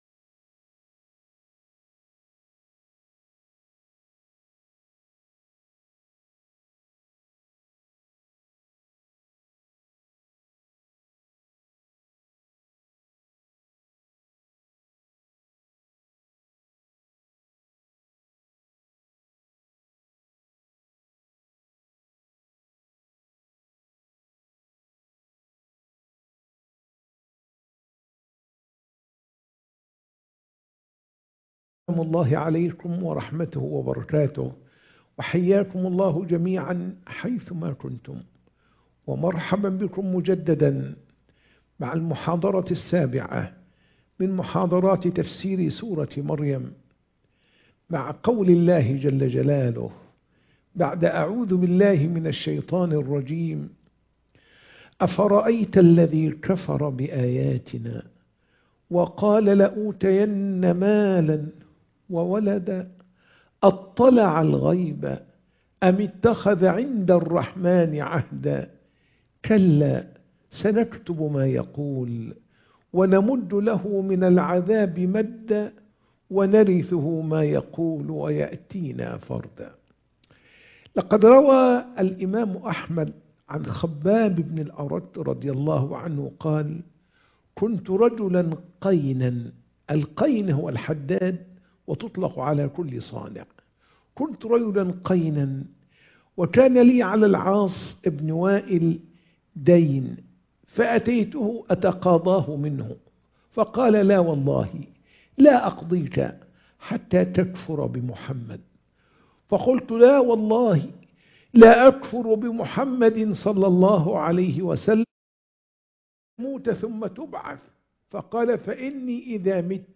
سورة مريم - المحاضرة 7